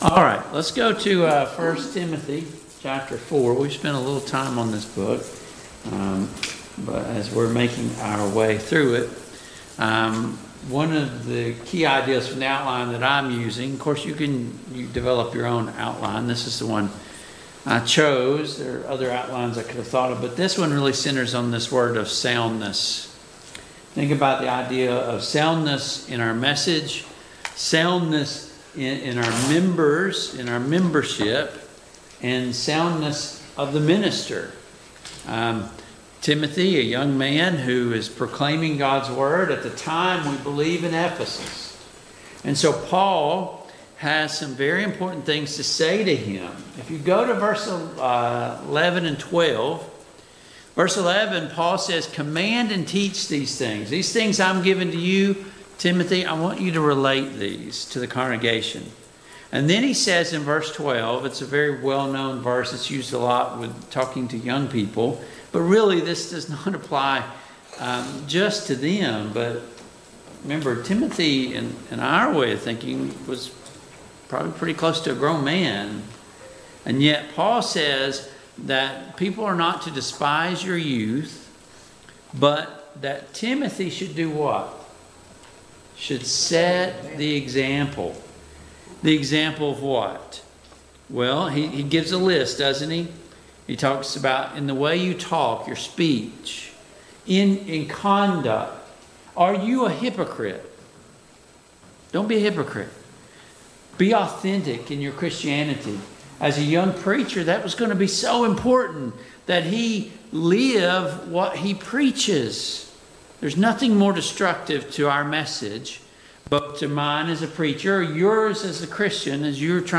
Passage: 1 Timothy 4:11-16, 1 Timothy 5:1-3 Service Type: Mid-Week Bible Study